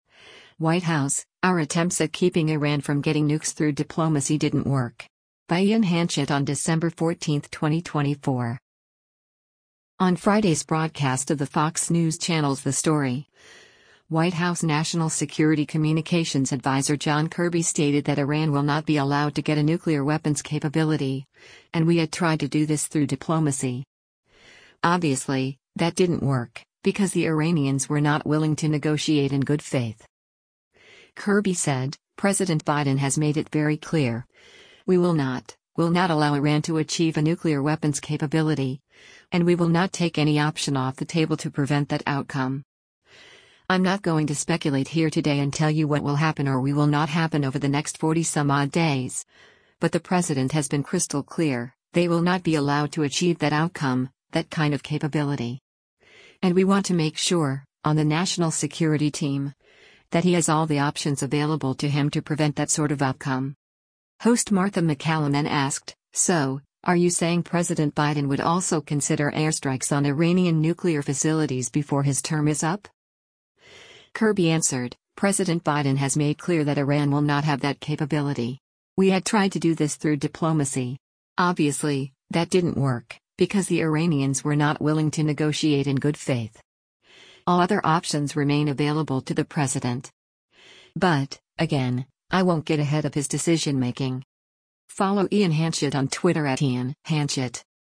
On Friday’s broadcast of the Fox News Channel’s “The Story,” White House National Security Communications Adviser John Kirby stated that Iran will not be allowed to get a nuclear weapons capability, and “We had tried to do this through diplomacy. Obviously, that didn’t work, because the Iranians were not willing to negotiate in good faith.”
Host Martha MacCallum then asked, “So, are you saying President Biden would also consider airstrikes on Iranian nuclear facilities before his term is up?”